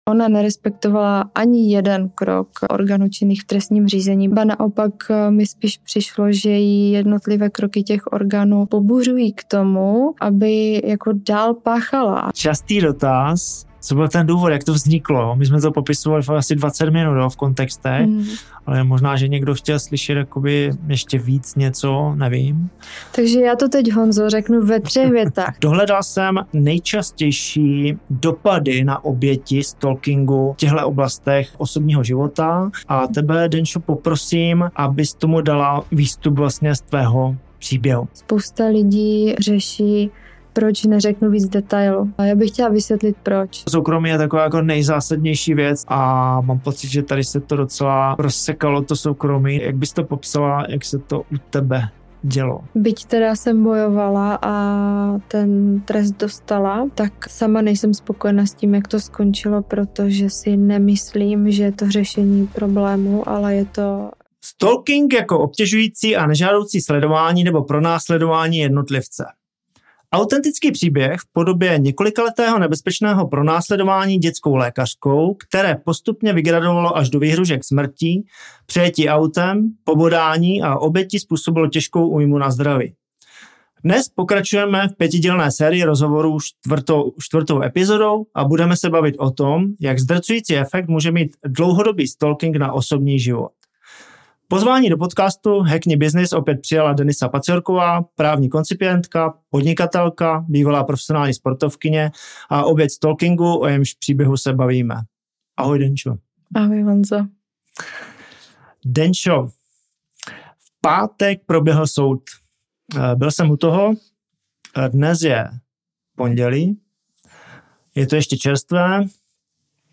série 5 rozhovorů